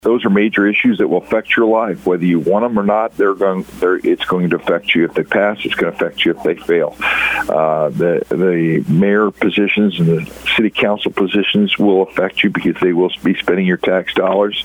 St. Francois County Clerk, Kevin Engler, says this makes April 8th one of the most important elections.